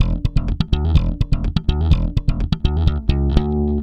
Index of /90_sSampleCDs/Best Service ProSamples vol.48 - Disco Fever [AKAI] 1CD/Partition D/BASS-SLAPPED